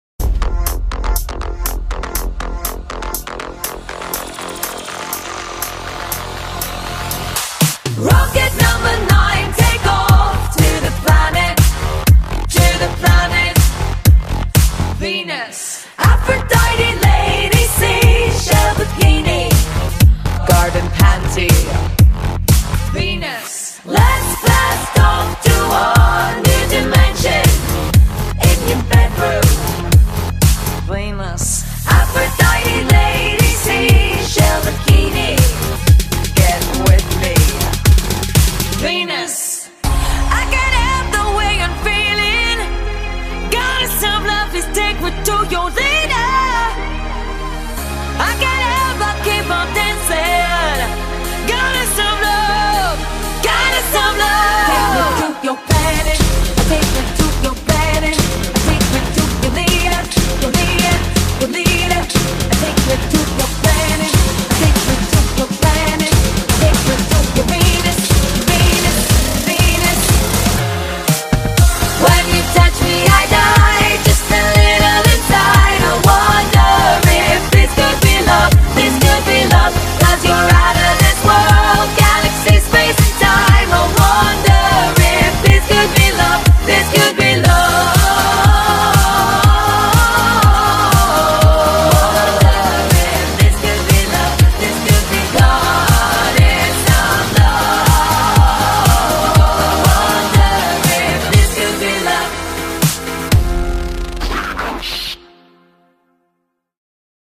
BPM121
Audio QualityCut From Video